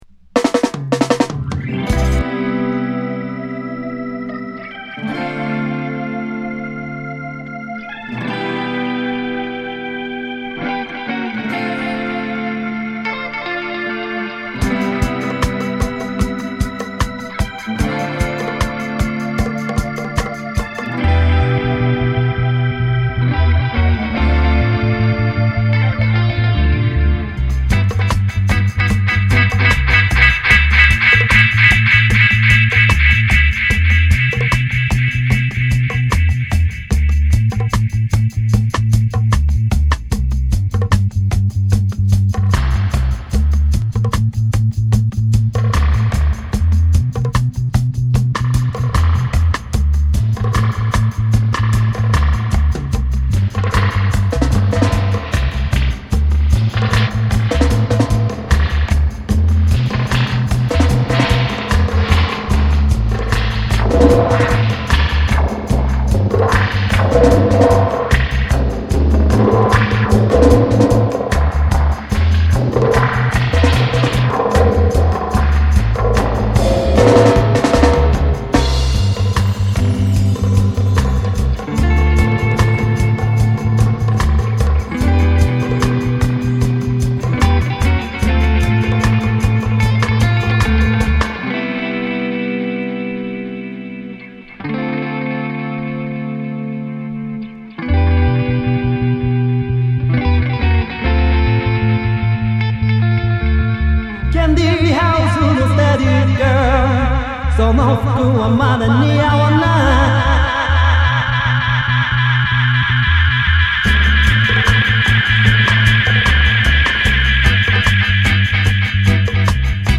Crucial Dub Mix